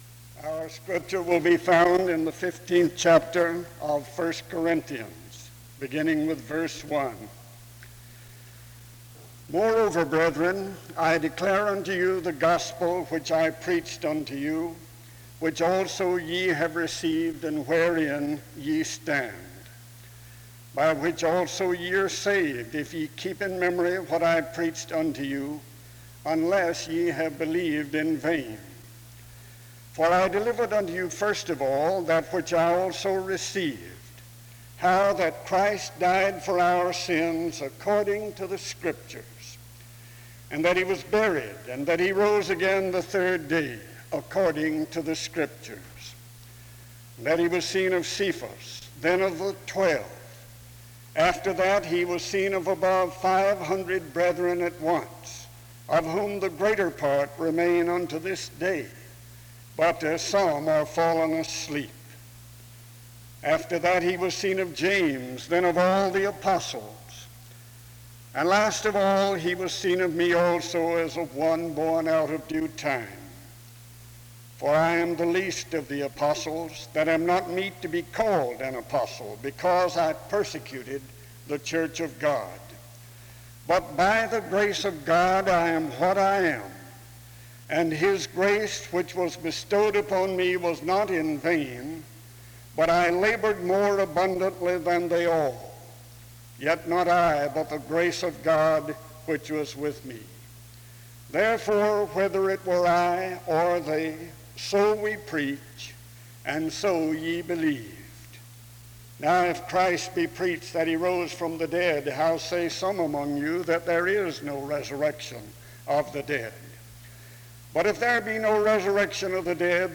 The service begins with an opening scripture reading from 0:00-2:52. A prayer is offered from 2:55-6:23. An introduction to the speaker is given from 6:35-9:21.